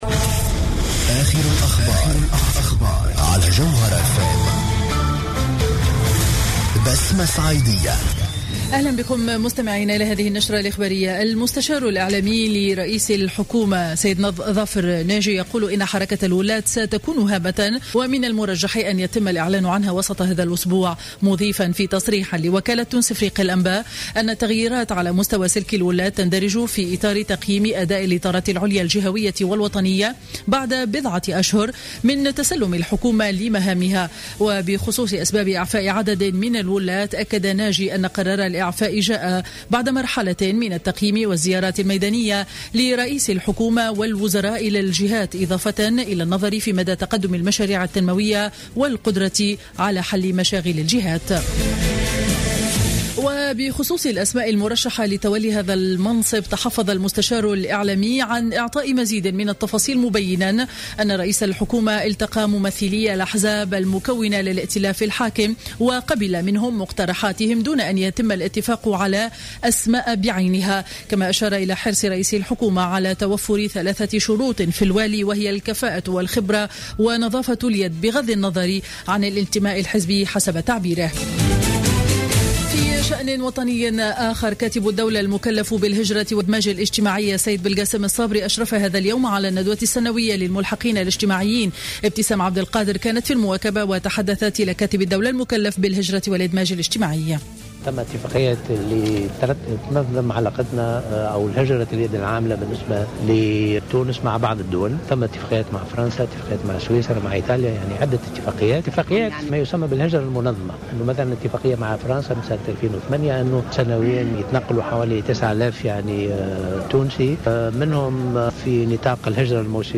نشرة أخبار منتصف النهار ليوم الإثنين 10 أوت 2015